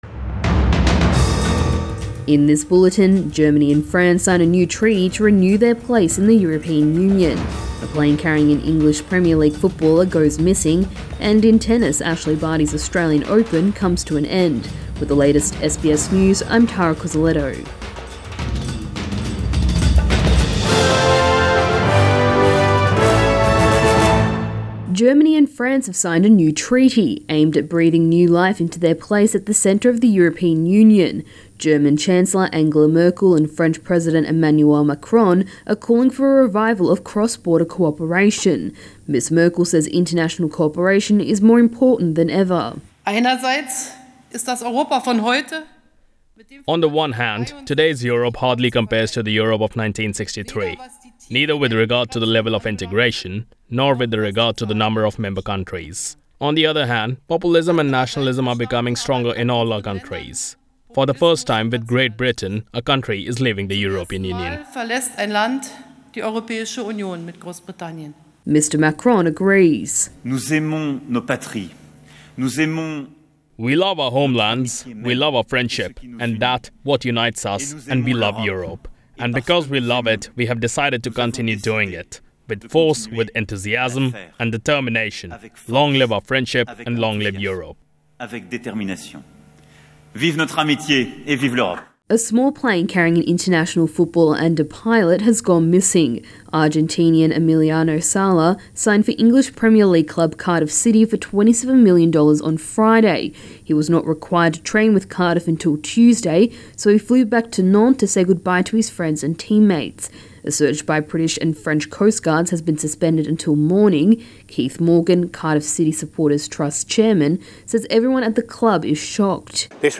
AM bulletin 23 January